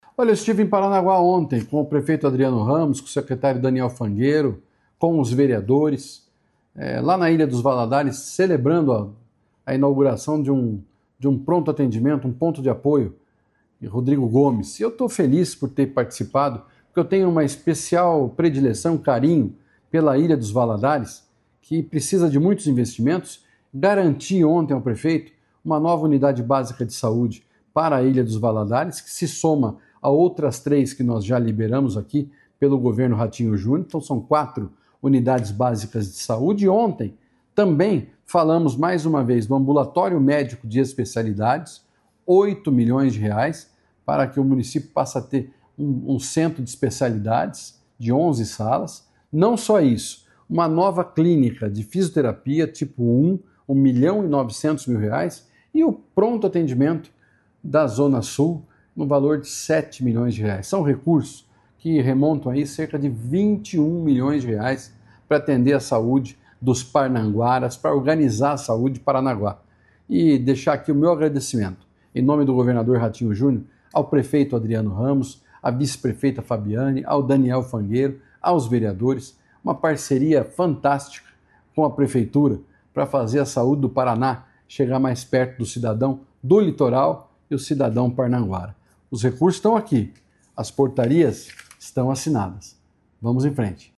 Sonora do secretário da Saúde, Beto Preto, sobre os investimentos em Paranaguá
BETO PRETO - PARANAGUÁ.mp3